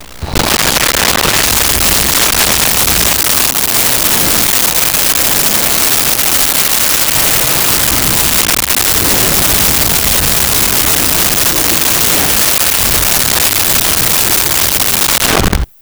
Monster Growl Screams
Monster Growl Screams.wav